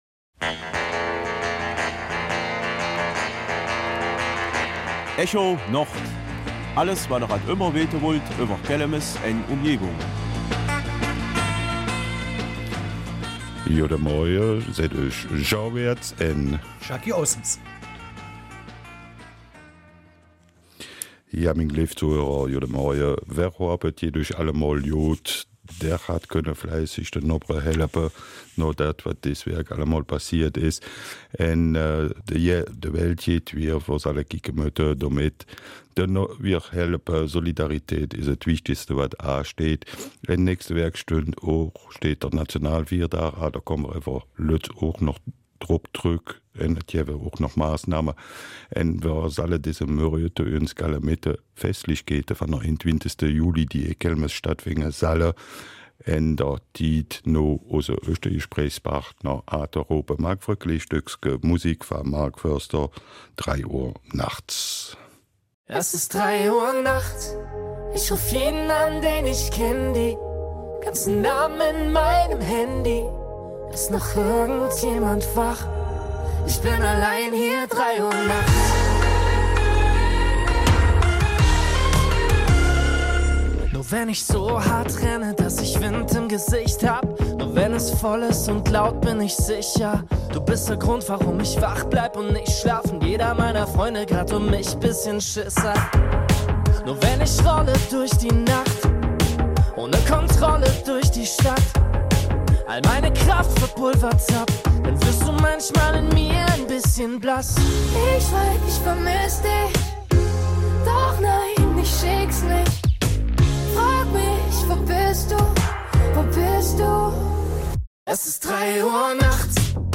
Nachdem das Fest letztes Jahr ausfallen musste, gibt es für dieses Jahr ein anderes Konzept mit gewissen Auflagen. Im Gespräch
Kelmiser Mundart